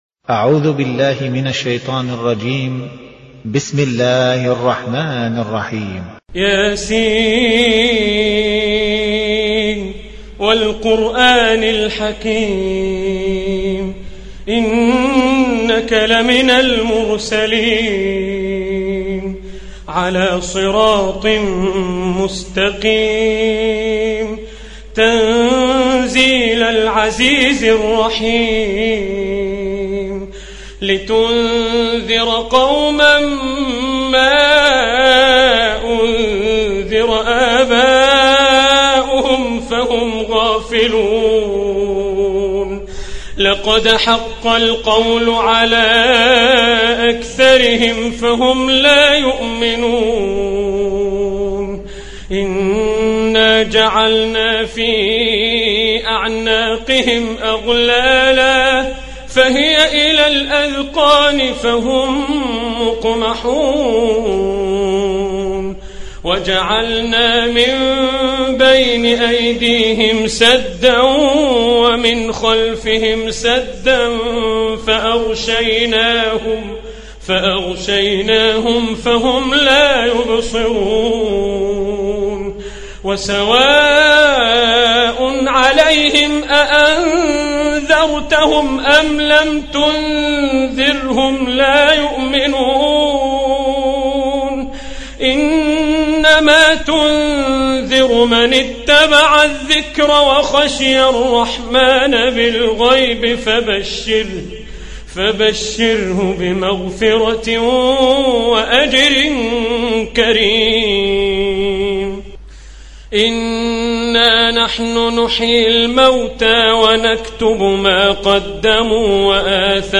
36. Surah Y�S�n. سورة يس Audio Quran Tarteel Recitation
Surah Repeating تكرار السورة Download Surah حمّل السورة Reciting Murattalah Audio for 36.